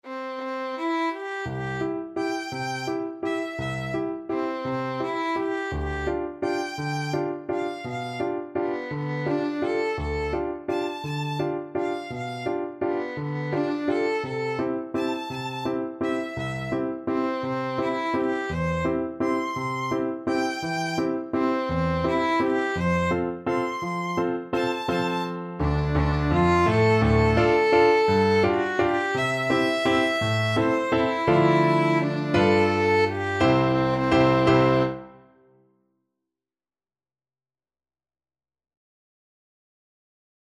Violin
3/4 (View more 3/4 Music)
=169 Steady one in a bar
C major (Sounding Pitch) (View more C major Music for Violin )
Classical (View more Classical Violin Music)